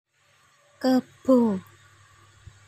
Contoh pengucapan
Kebo .mp3